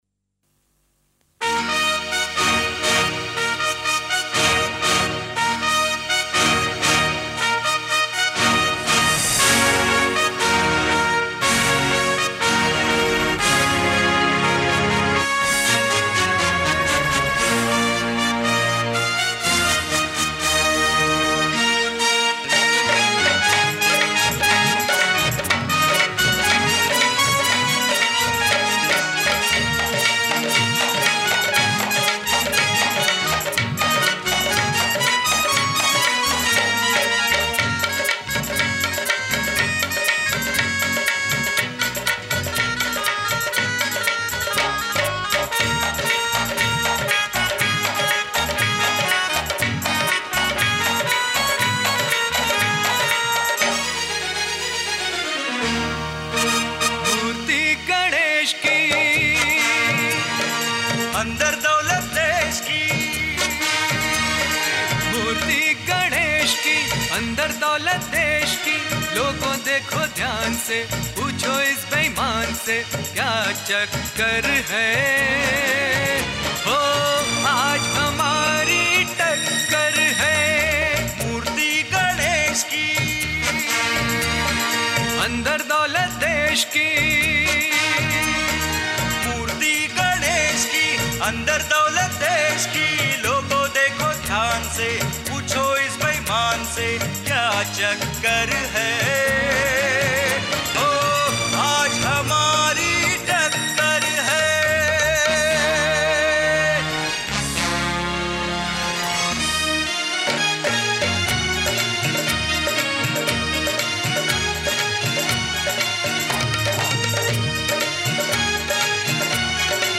Devotional Song